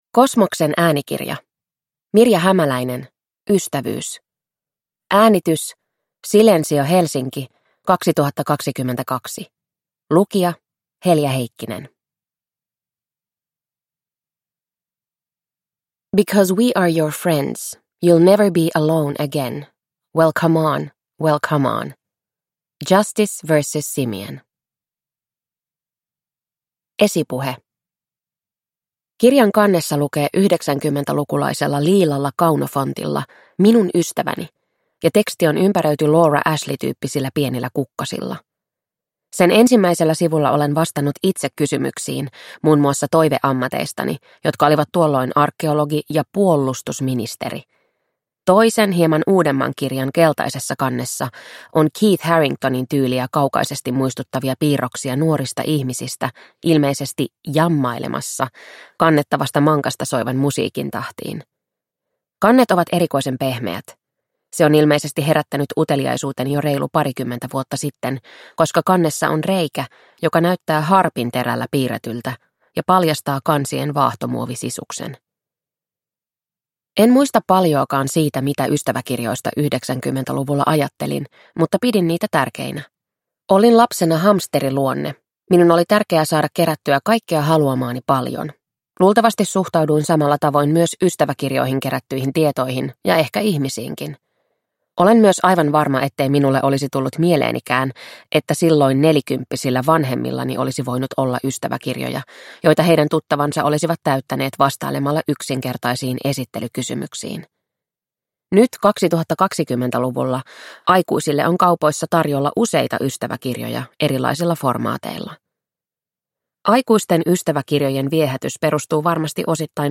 Ystävyys – Ljudbok